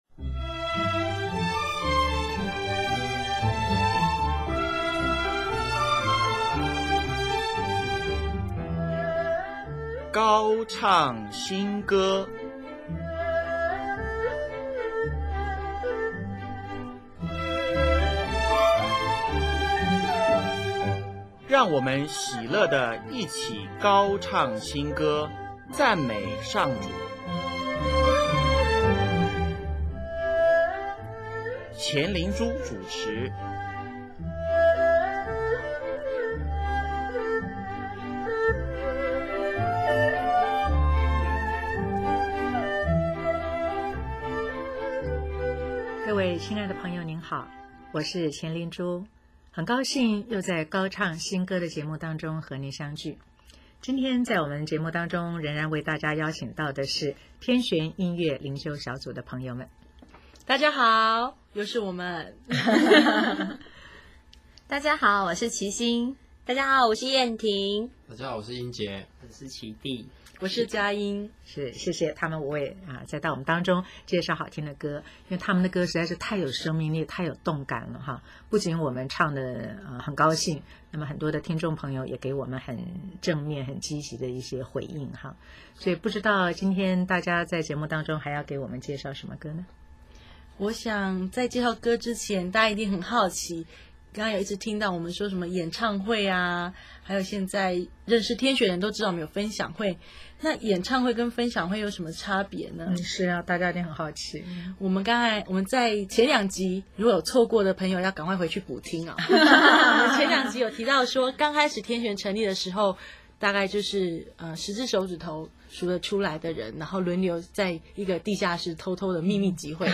【高唱新歌】59|专访天旋音乐灵修小组(三)：荣耀归于谁？